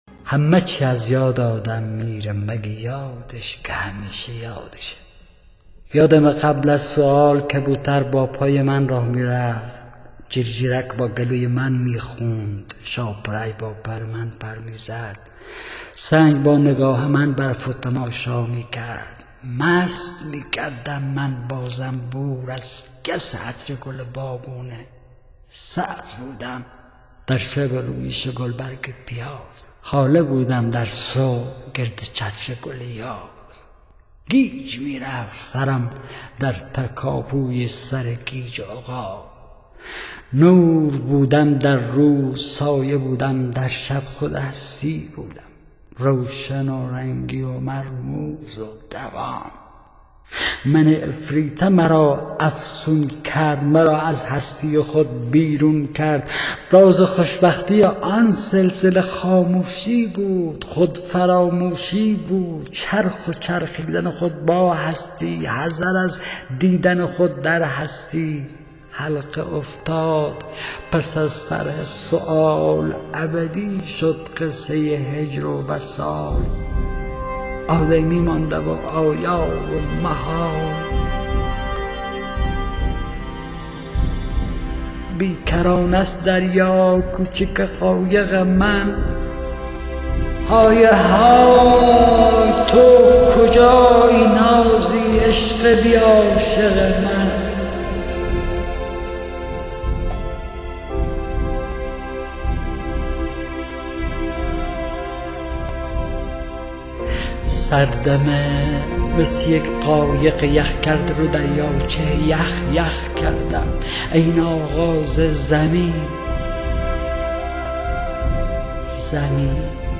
• دکلمه حسین پناهی